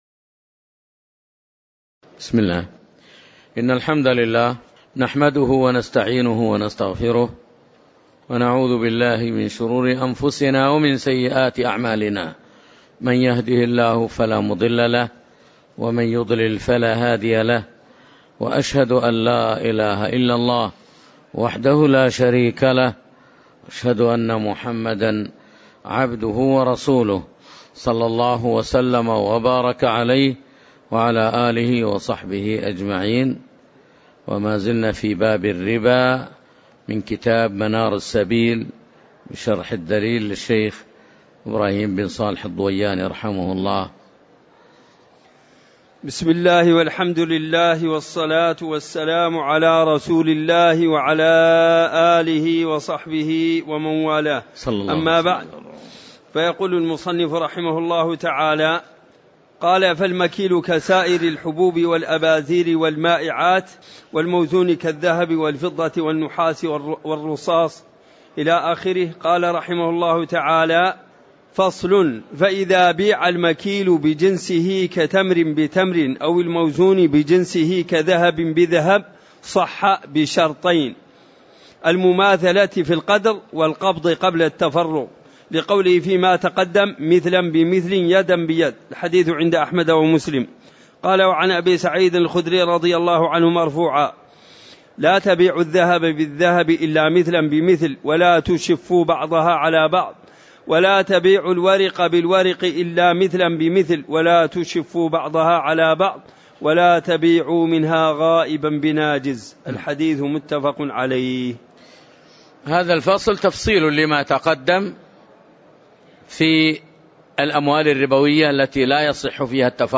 تاريخ النشر ٤ صفر ١٤٤٠ هـ المكان: المسجد النبوي الشيخ